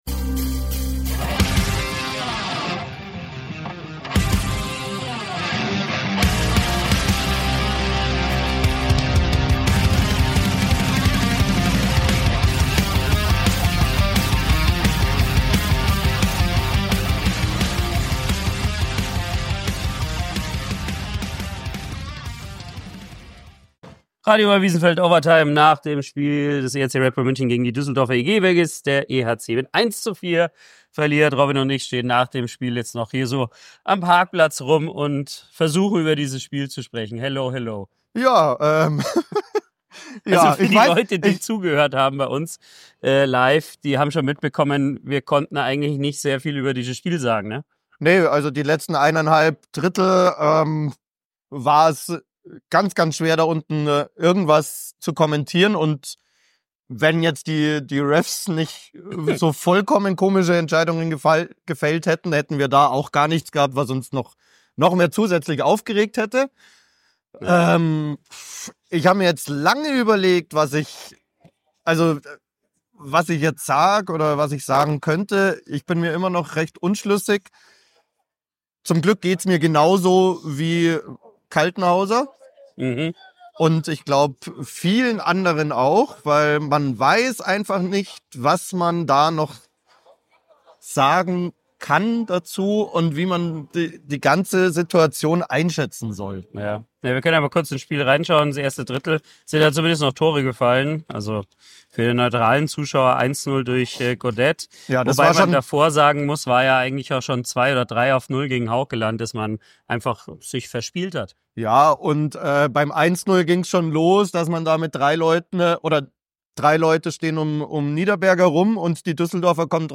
Danach hört ihr die längste Pressekonferenz dieser Saison mit vielen Fragen der Journalisten und danach das Spieltagsinterview mit Patrick Hager.